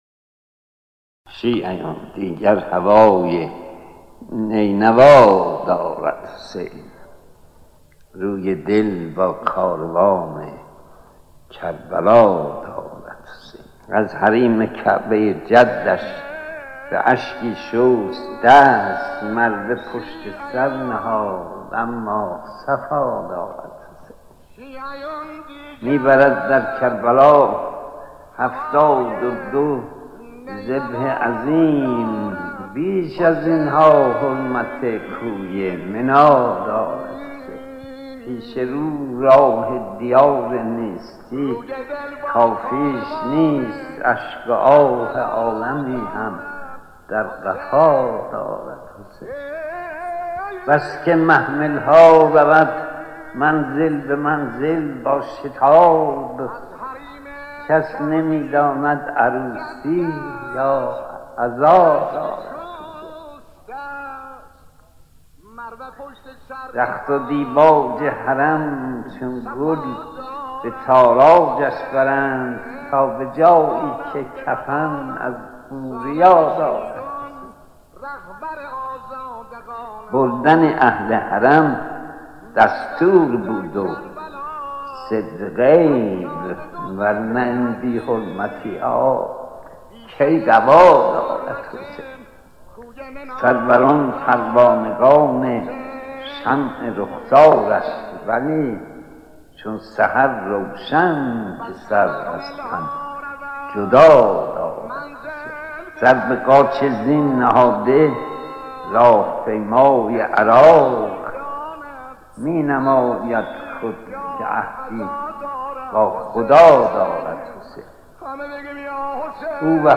پادکست/ «کاروان کربلا» را با صدای شهریار بشنوید